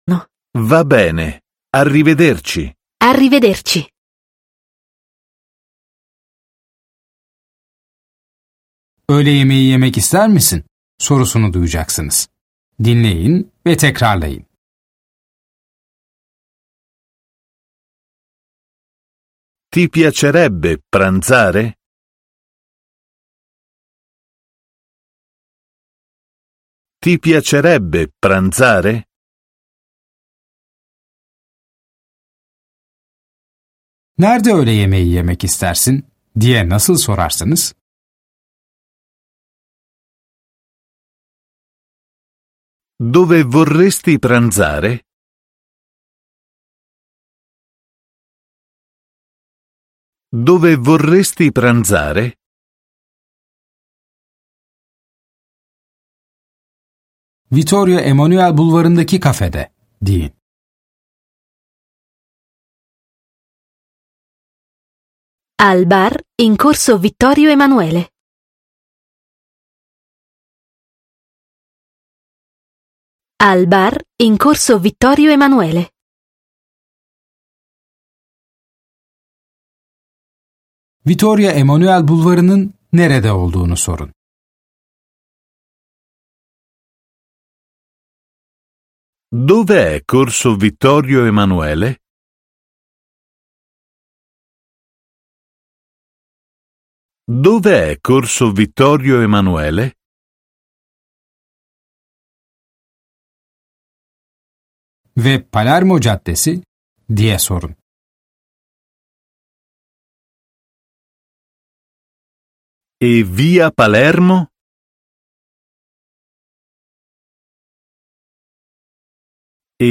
Dersler boyunca sizi yönlendiren bir kişisel eğitmeniniz olacak. Ana dili İtalyanca olan iki kişi de sürekli diyalog halinde olacaklar. Yönlendirmeniniz size söz verdikçe gerekli tekrarları yapın ve sorulan sorulara cevap verin.